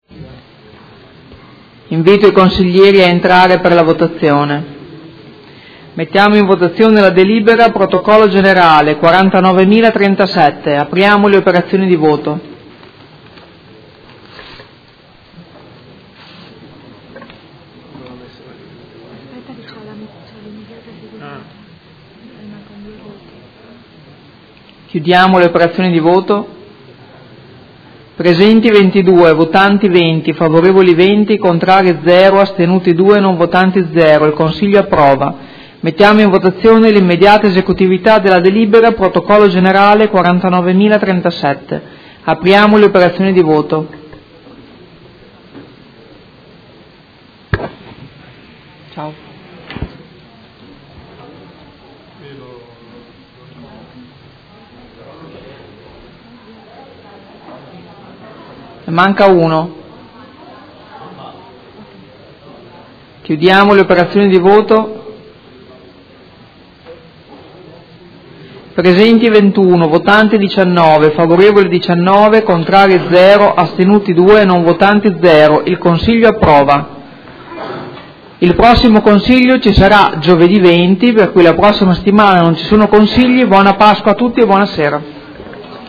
Presidentessa — Sito Audio Consiglio Comunale
Presidentessa